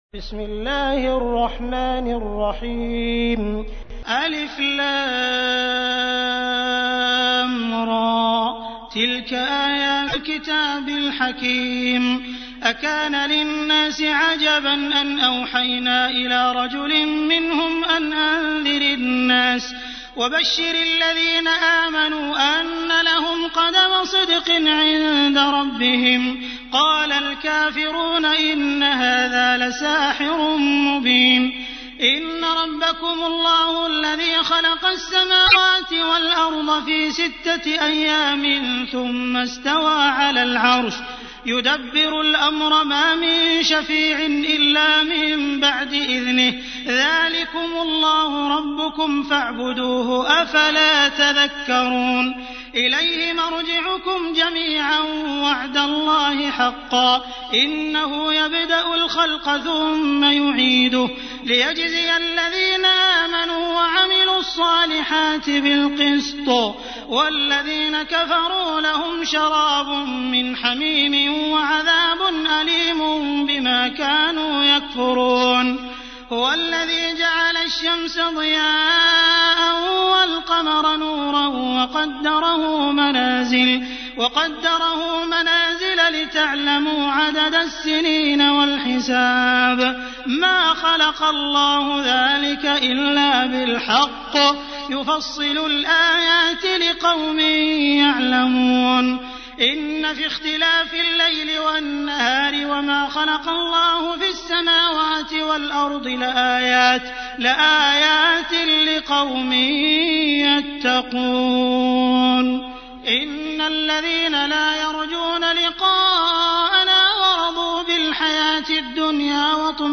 تحميل : 10. سورة يونس / القارئ عبد الرحمن السديس / القرآن الكريم / موقع يا حسين